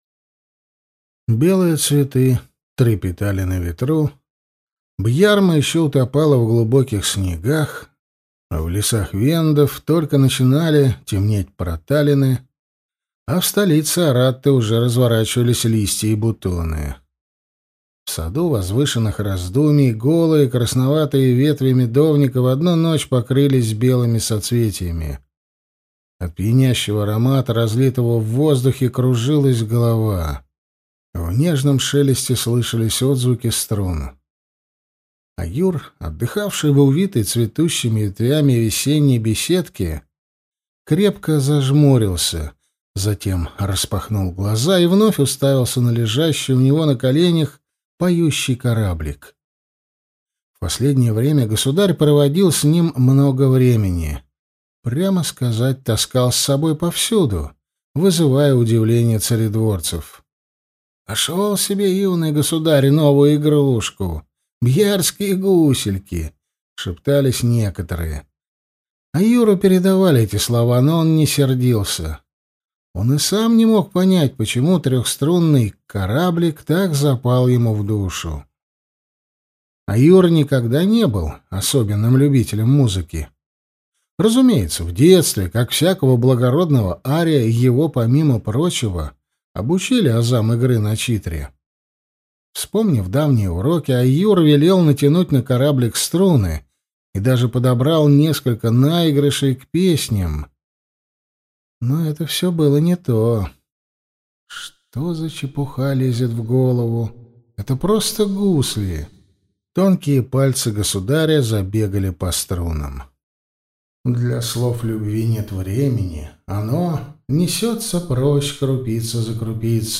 Аудиокнига Аратта. Книга 7. Золотые корабли | Библиотека аудиокниг